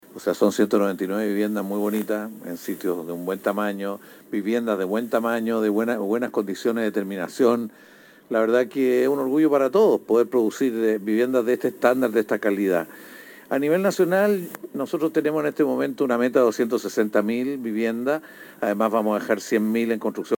En una ceremonia emotiva y llena de alegría, se llevó a cabo la inauguración y entrega de llaves del proyecto de vivienda «Construyendo Juntos», que fue financiado por el Fondo Solidario de Elección de Vivienda, Decreto Supremo 49 del MINVU.
Sobre esto, el Ministro de Vivienda, Carlos Montes, indicó que